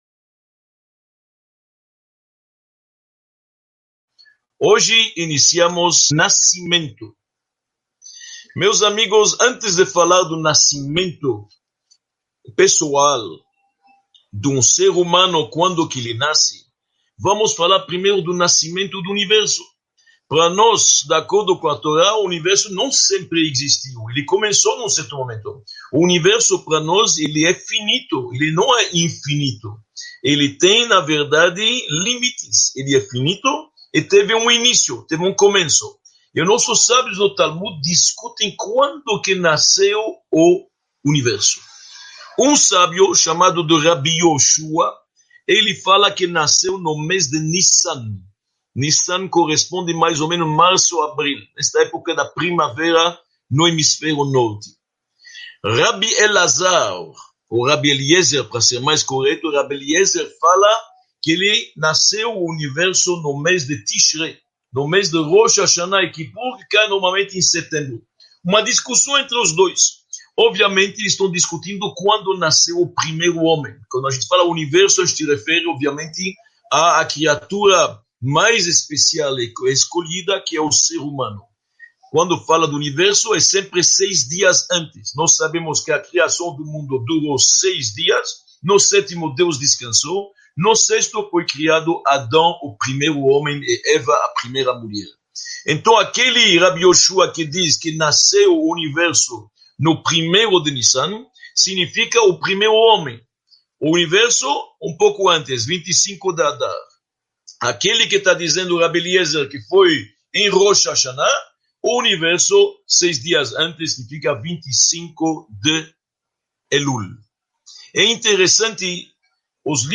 01 – Nascer e Morrer | Os Mistérios do Universo – Aula 01 | Manual Judaico